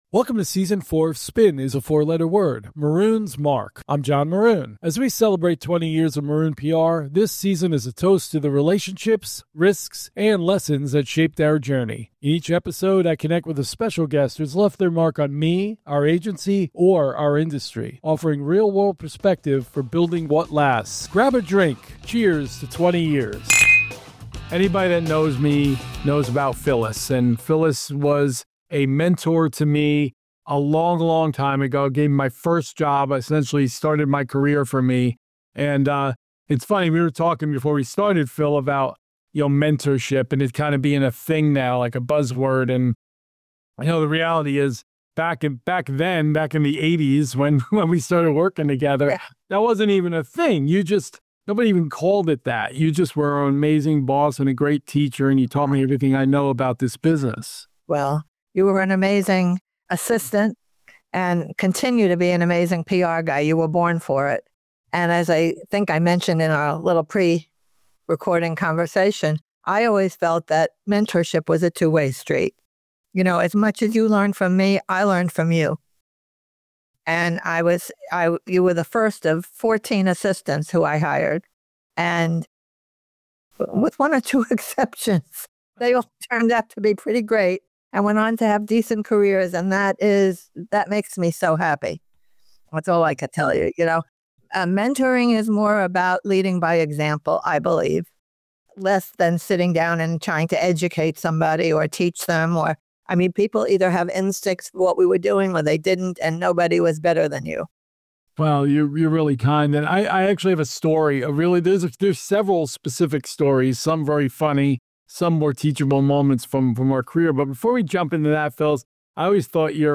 From defining career moments to lessons in leadership and relationships, their conversation reveals what true mentorship looks like and why its impact lasts a lifetime.